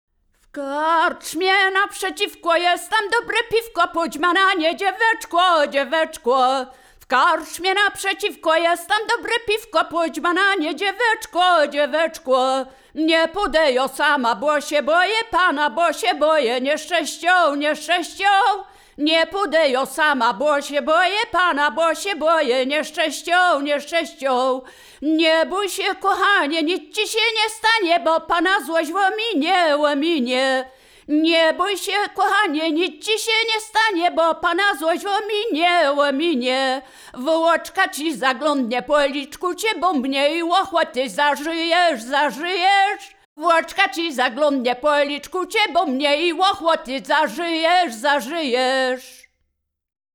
Wielkopolska
województwo wielkopolskie, powiat gostyński, gmina Krobia, wieś Posadowo
liryczne miłosne